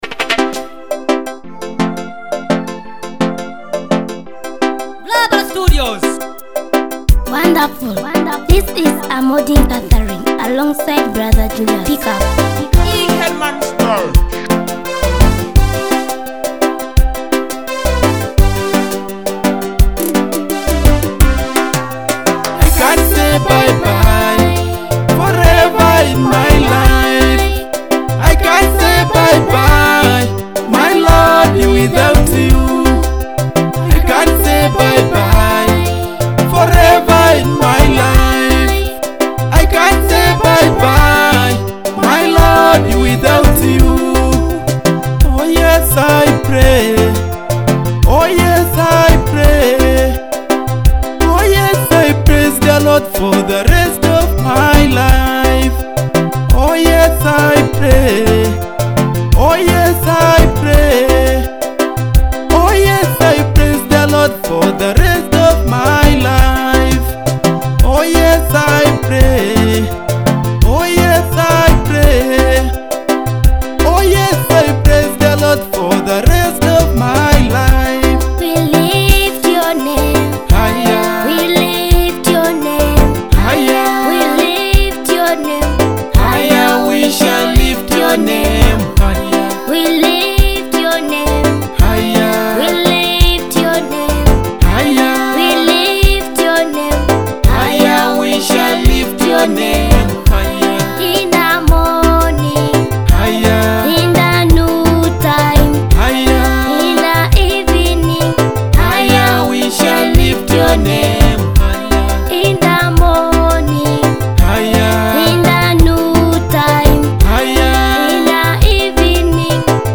a powerful gospel anthem exalting Jesus.